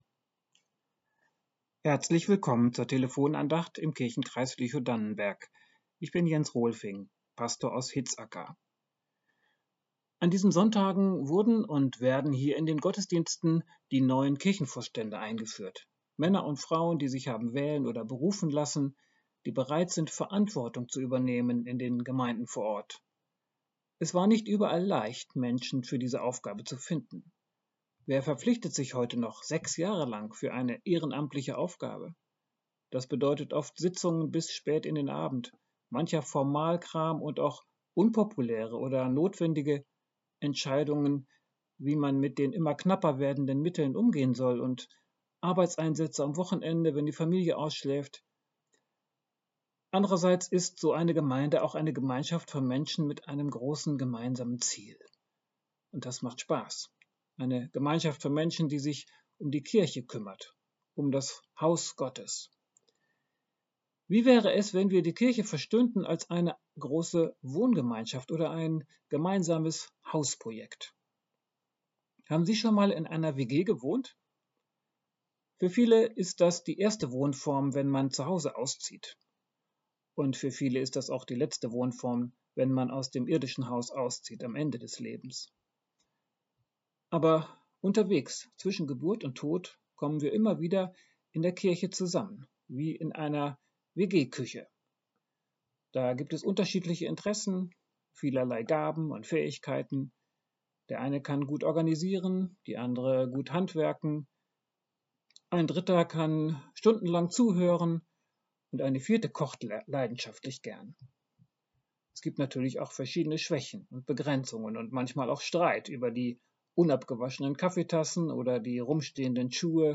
Die Kirchen-WG ~ Telefon-Andachten des ev.-luth. Kirchenkreises Lüchow-Dannenberg Podcast